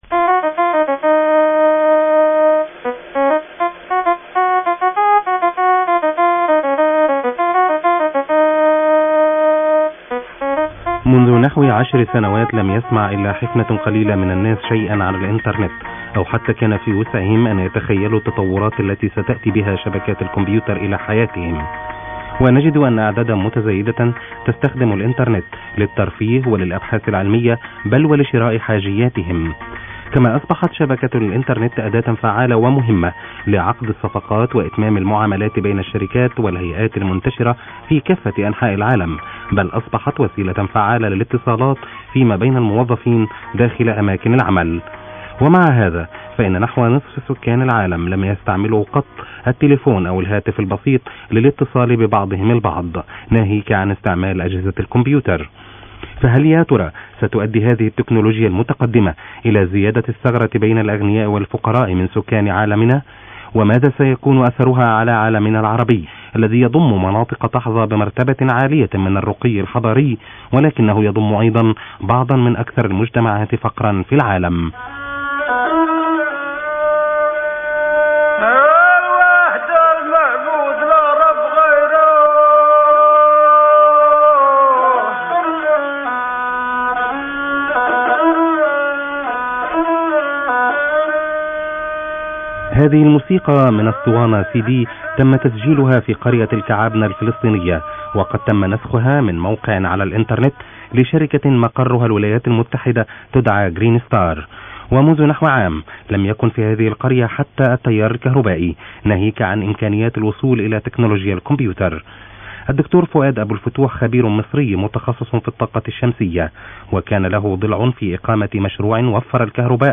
The BBC World Arabic Service radio broadcast about Greenstar, April 2000 compressed MP3 format, approx. 7 minutes, 1.6 Mbytes file size click here to download the audio file click here to download a free MP3 audio player, if needed